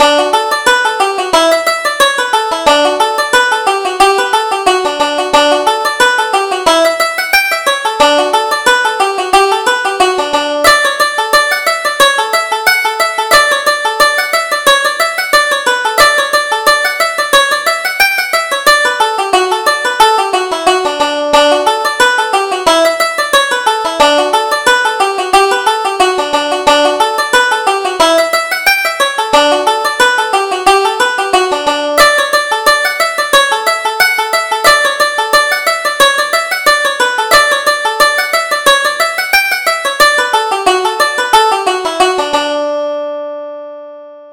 Reel: Lady Forbes